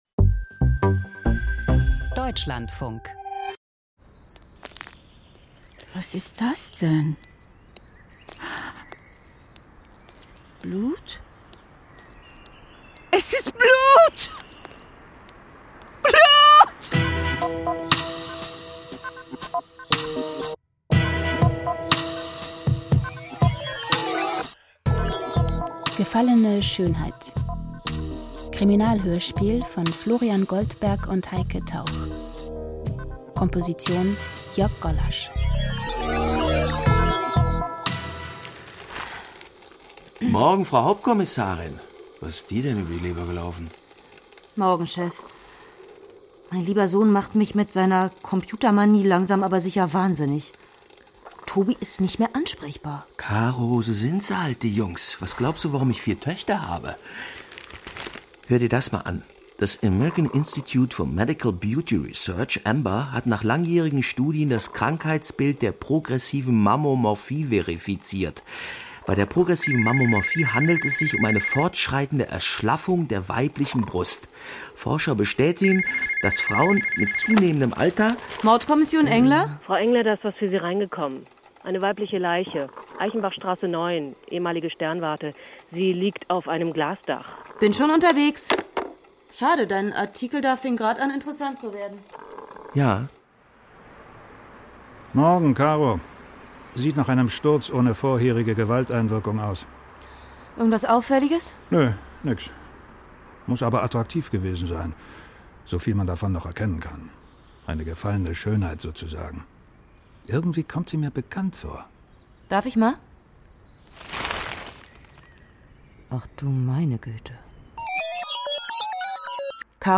Klangkunst von der documenta fifteen - The Border Farce Collaboration